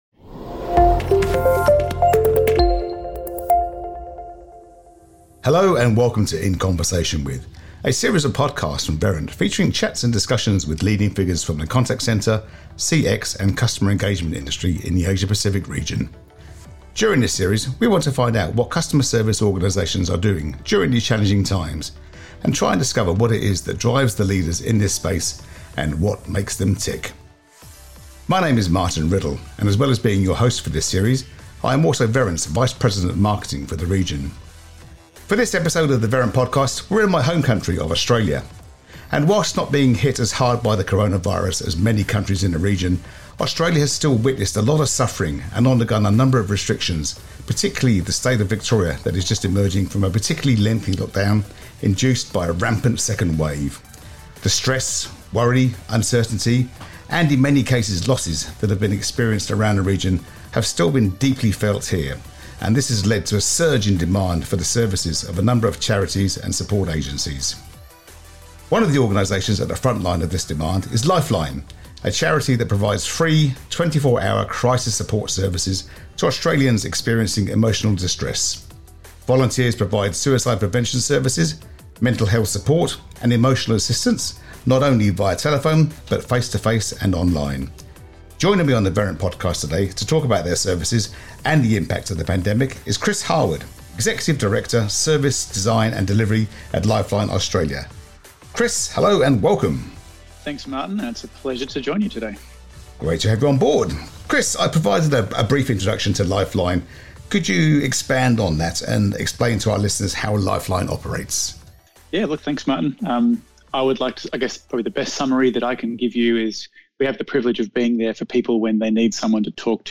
In conversation with is a series of podcasts from Verint featuring chats and discussions with leading figures from the contact centre, CX and customer engagement industr…